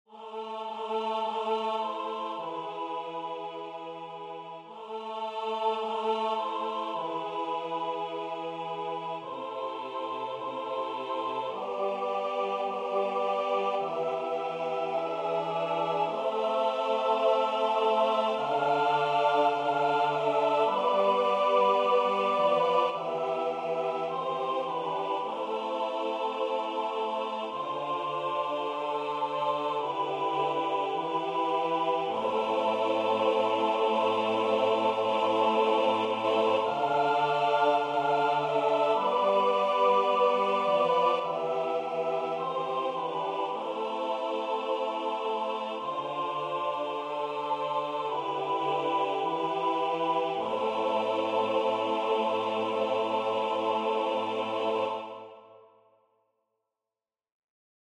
• Catégorie : Chants de Prière universelle